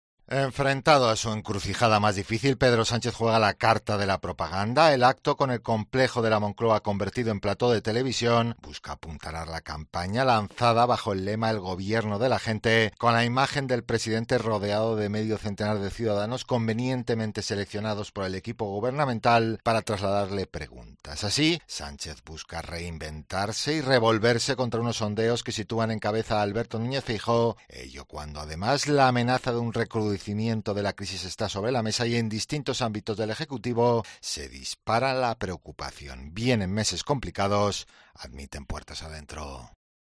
Objetivo de La Moncloa: “Reinventar” a Sánchez para frenar el desgaste. Crónica